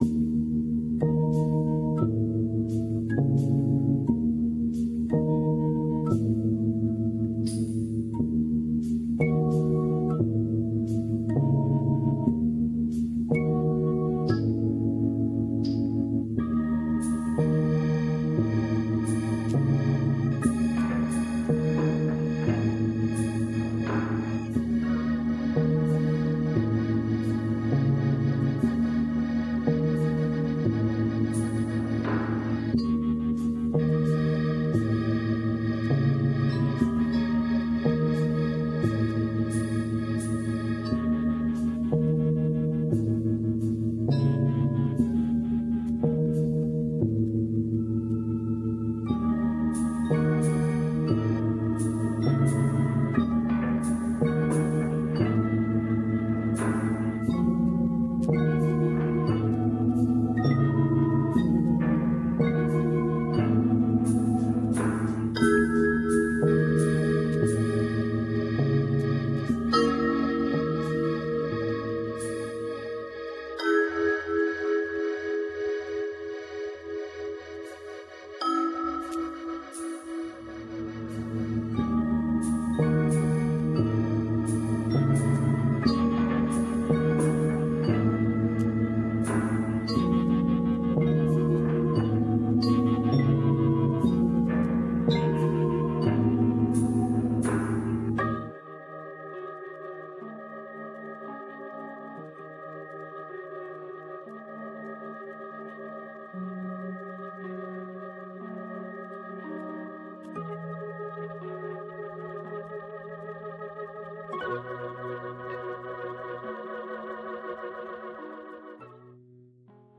dark, lush and more than a little offbeat.